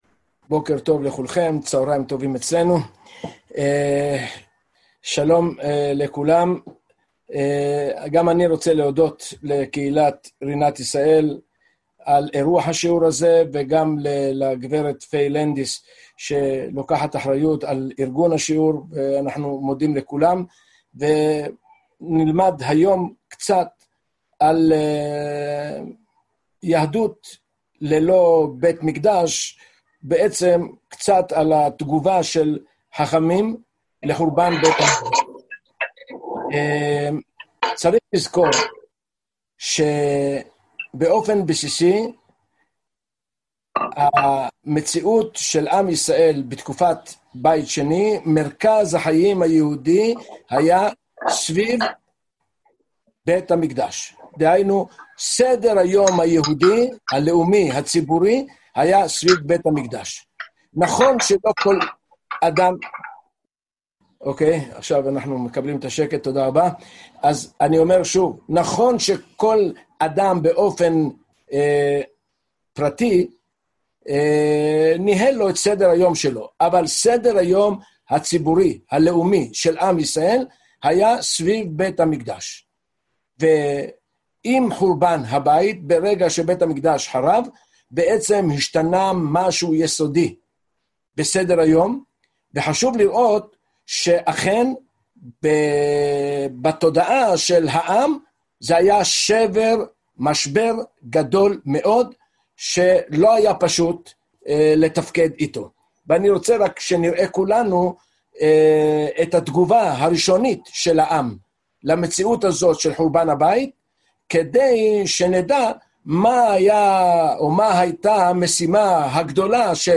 אנו מצטערים על הרעשים בדקה הראשונה של השיעור.